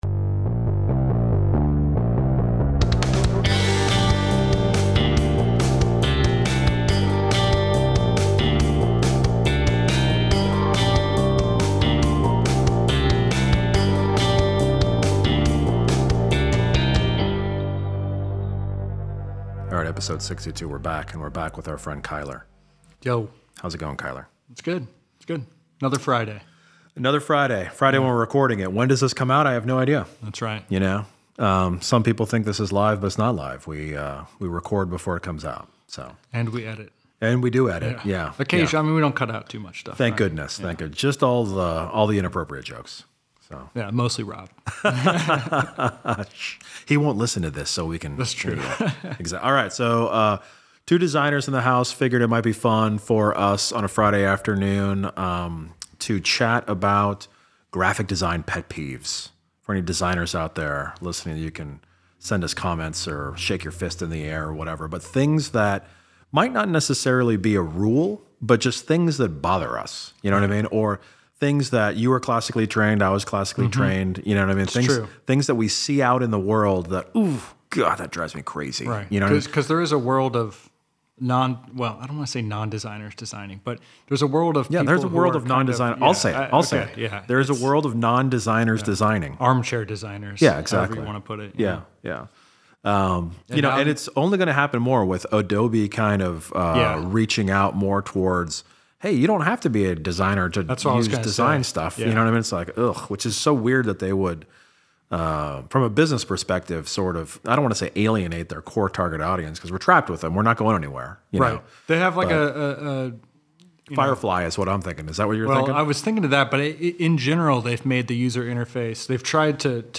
Two graphic designers share what design issues irritate them the most.